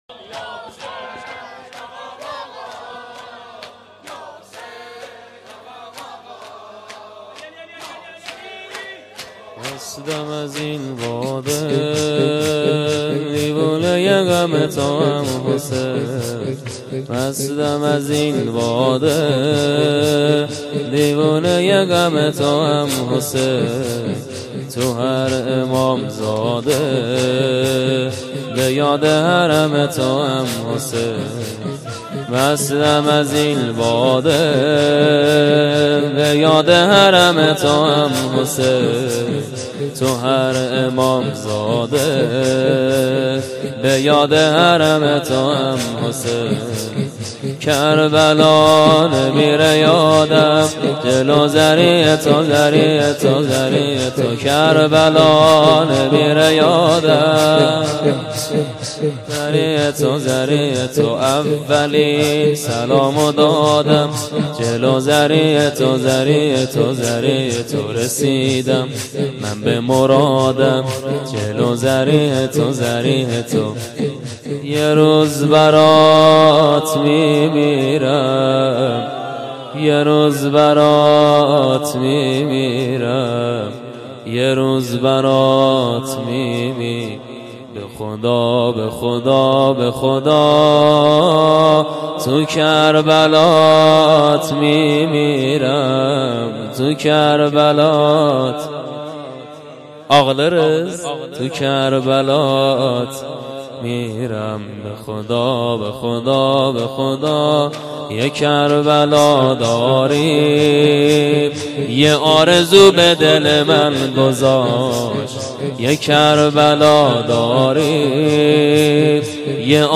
شور مستم از این باده محرم96منزل شهید